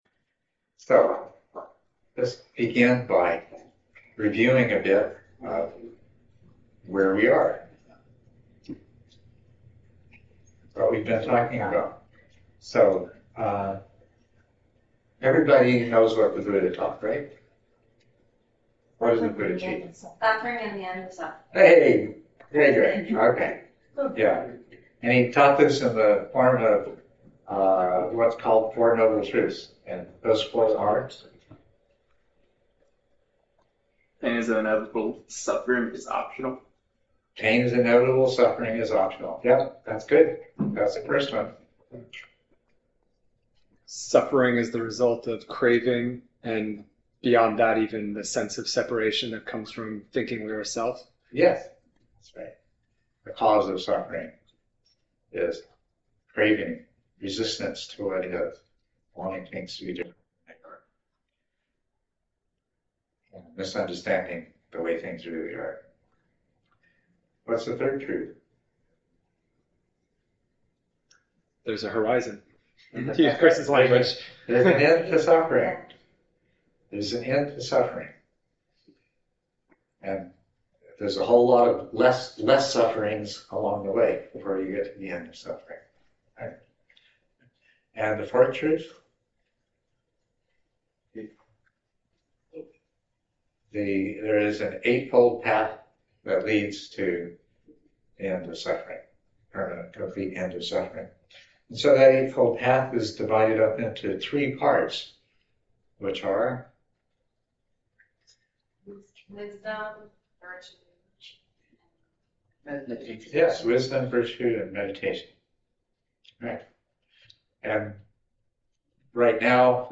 Edit talk Download audio (mp3) Download original audio Listen to cleaned audio * Audio files are processed to reduce background noise, and provide (much) better compression.